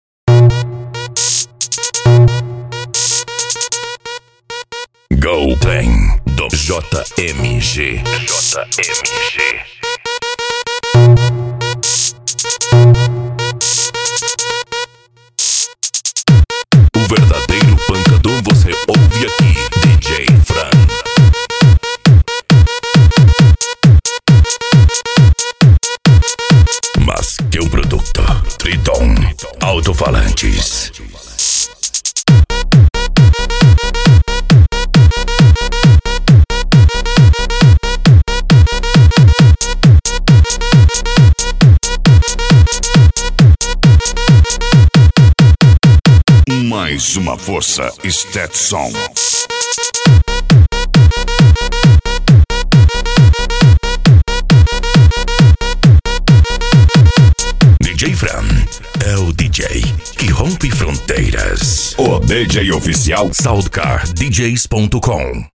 Bass
Cumbia
PANCADÃO
Remix